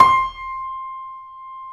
Index of /90_sSampleCDs/Roland - Rhythm Section/KEY_YC7 Piano mf/KEY_mf YC7 Mono
KEY C 5 F 0J.wav